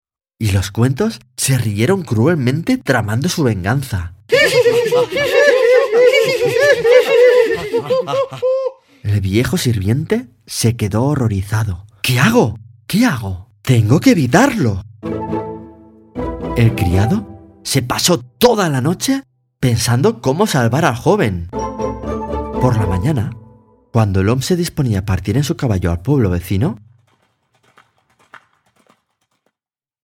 4 Audiolibros: